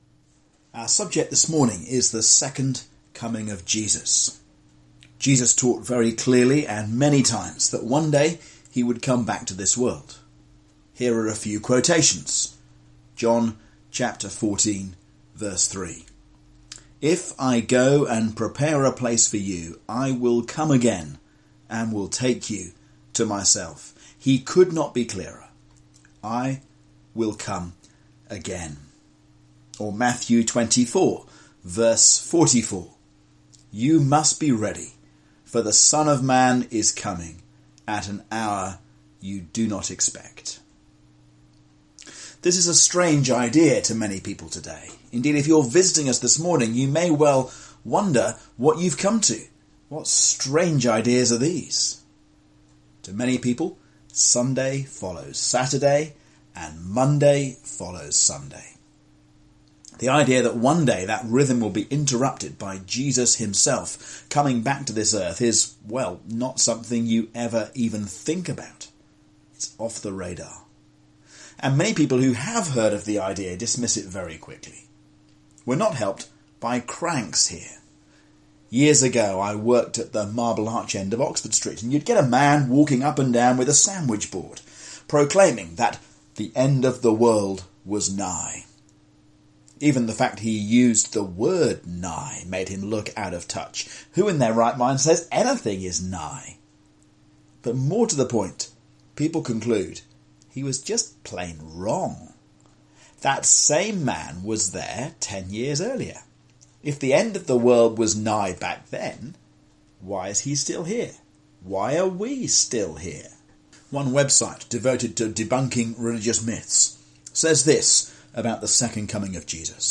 A sermon on 2 Peter 3:1-9